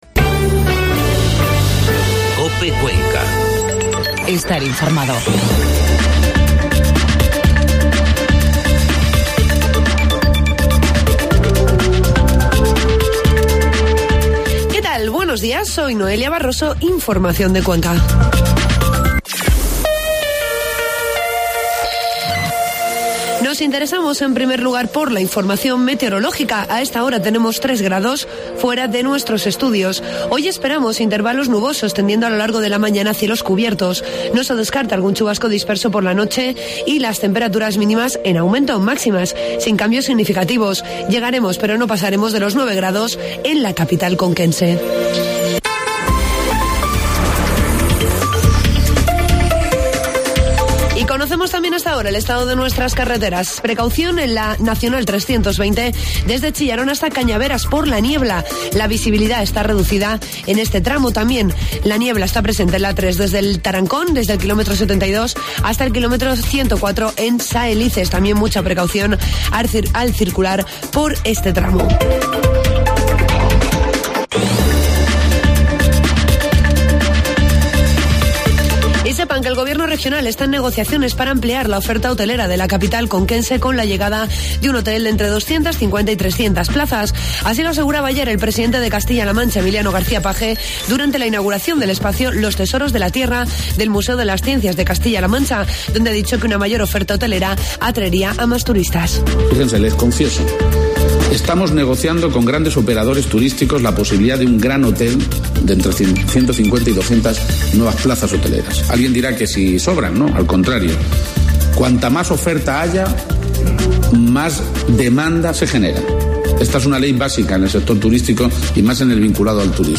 Informativo matinal 18 de diciembre